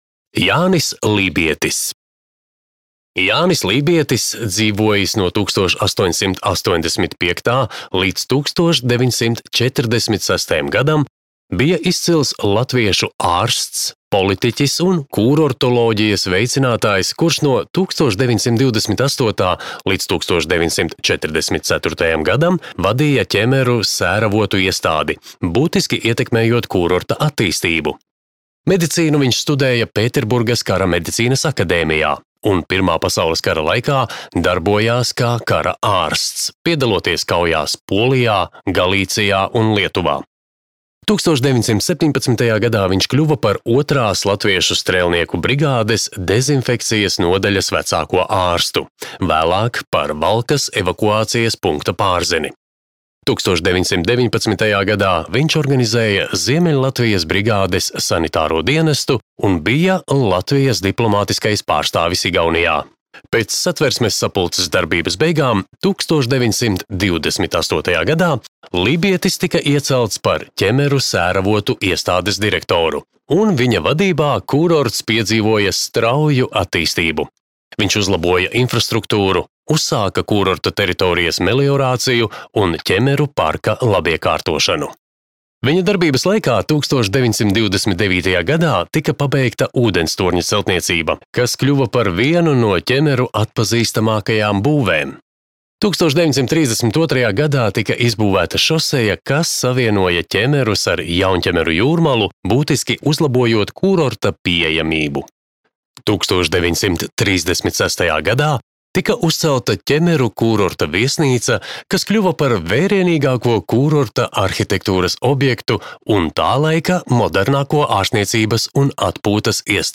Ķemeru kūrorta parka audiogids